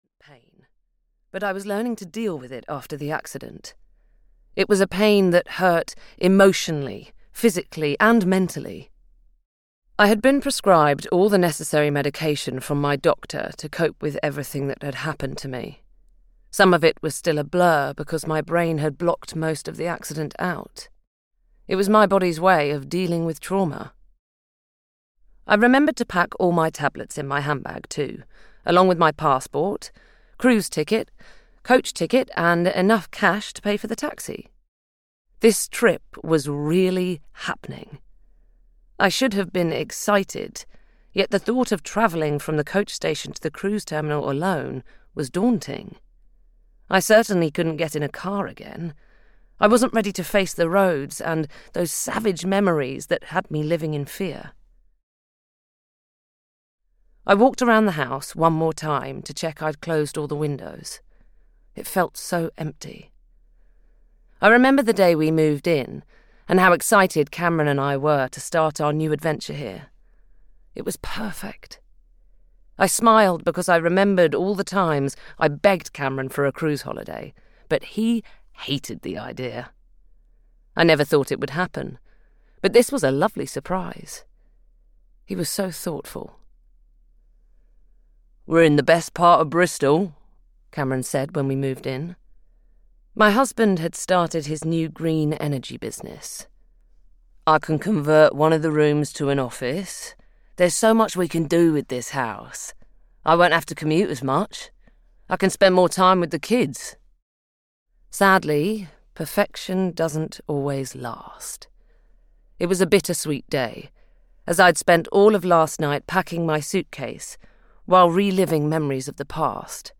Behind Her Smile (EN) audiokniha
Ukázka z knihy